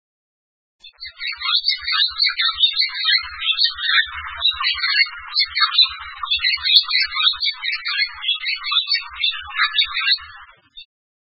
2125e「鳥の鳴声」
〔ツバメ〕チュビッ／ツピー／草原・湖沼・林縁などで見られる，普通・夏鳥，15〜18